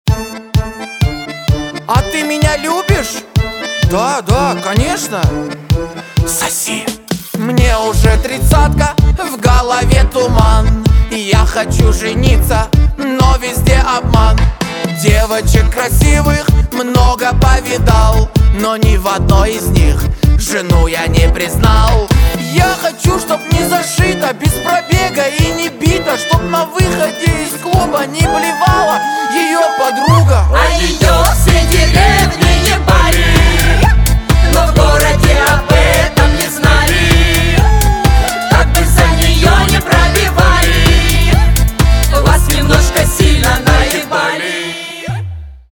• Качество: 320, Stereo
мужской вокал
женский вокал
шуточные
гармонь